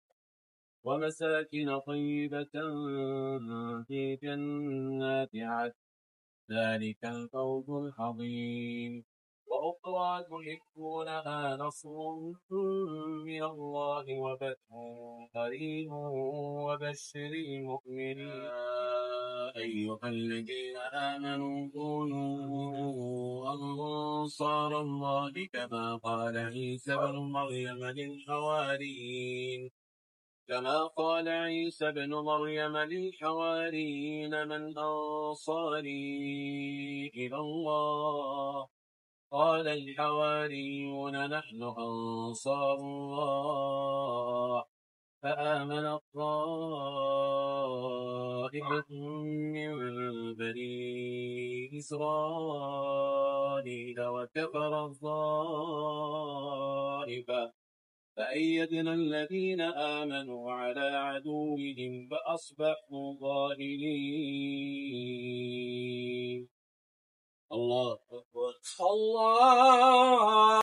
صلاة المغرب
تلاوات الشيخ سعود الشريم خارج الحرم